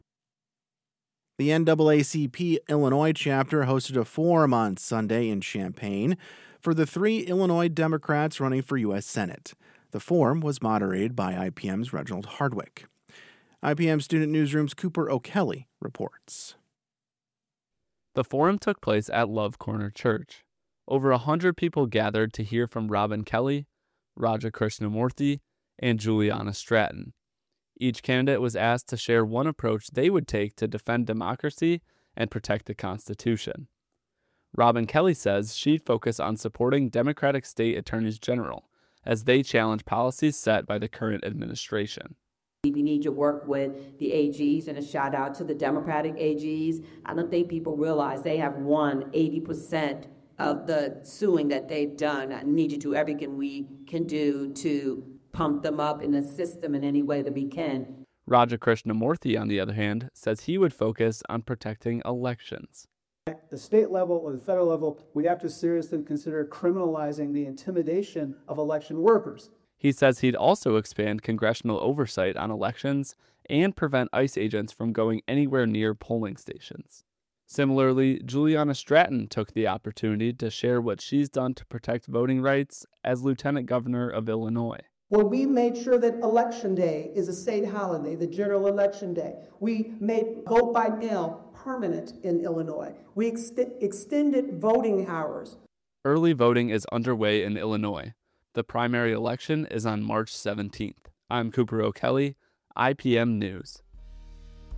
CHAMPAIGN – Over 100 people showed up at Love Corner Church in Champaign Sunday to hear three Democratic candidates for U.S. Senate share their top priorities and answer questions from the audience.